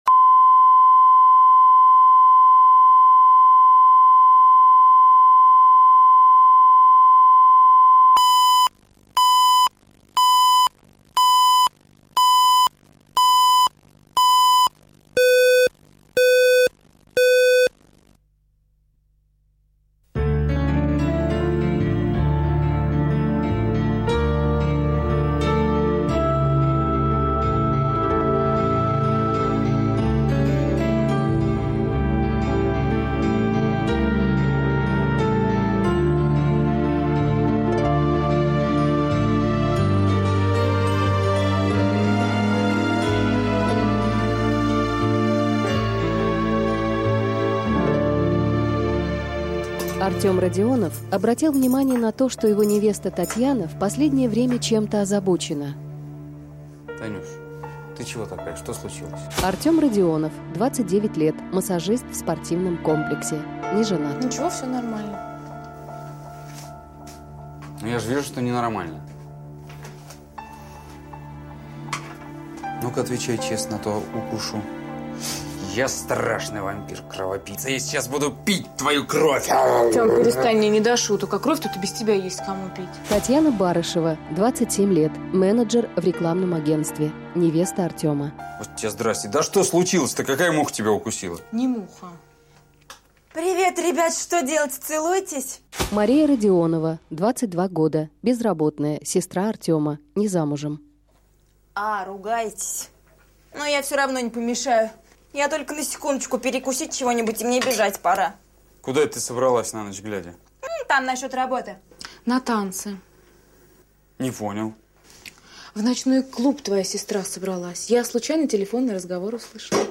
Аудиокнига Сестра на первом месте | Библиотека аудиокниг